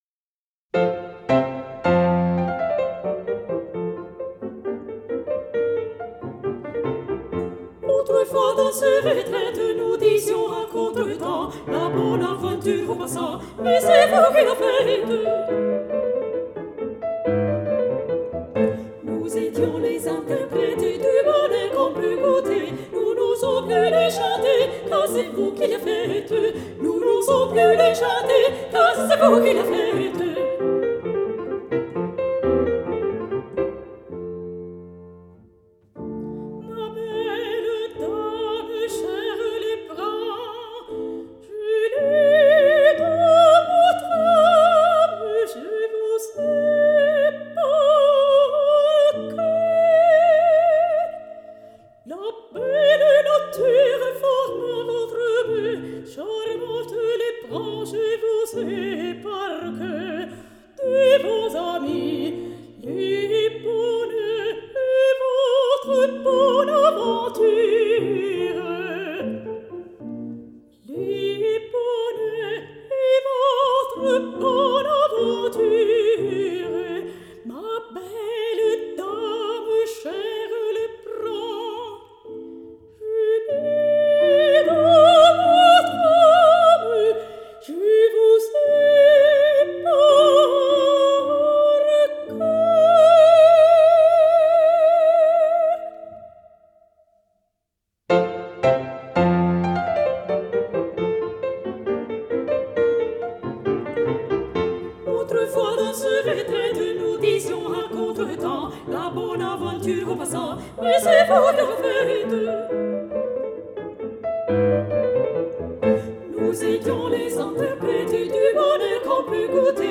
soprani
pianoforte
violino
chitarra